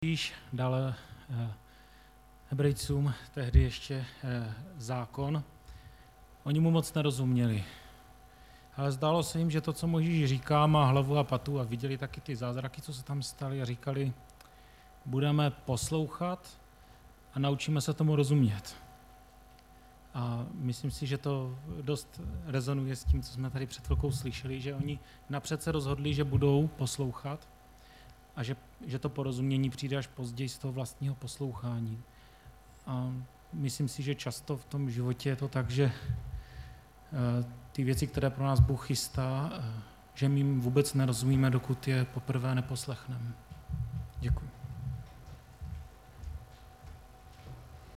Středeční vyučování
Záznamy z bohoslužeb